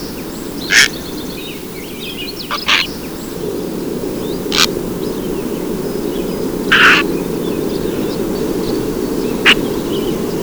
"Garzón Cenizo"
"Great Blue Heron"
Ardea herodias
garzon-cenizo.wav